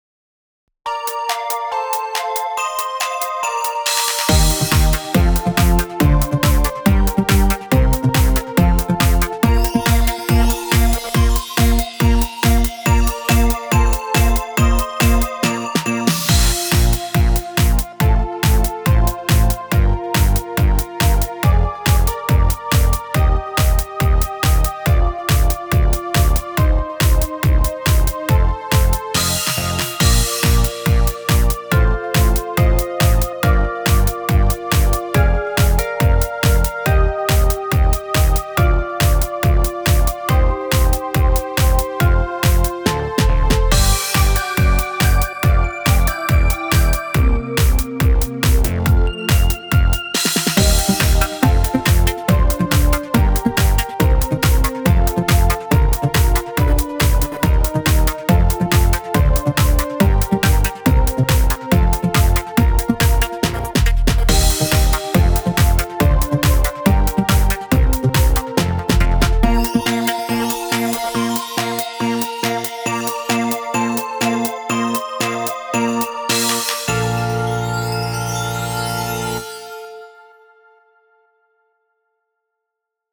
明るい楽曲
【イメージ】明るい、オープニング など
【備考】こちらの楽曲はループしません